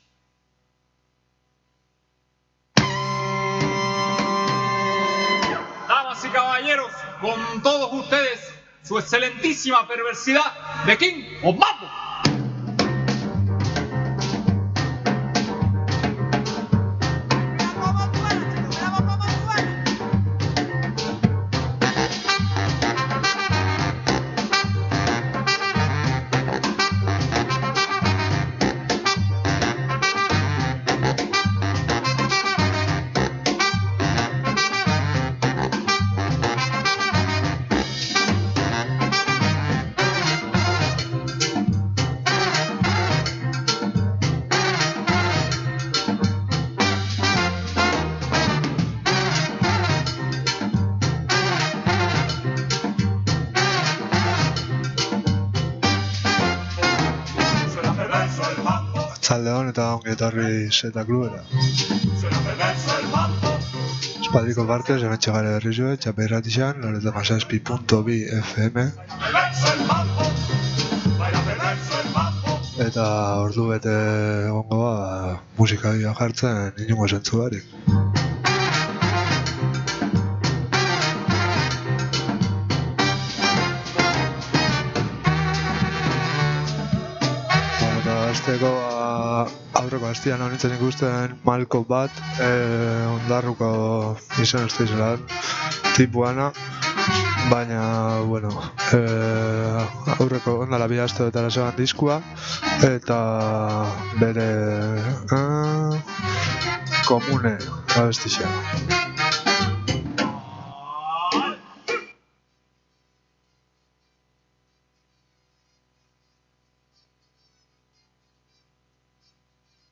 Hamabostean behin irekiko da Z Klub-a, momentuz, disko entzunaldi inprobisatuak eskaintzeko.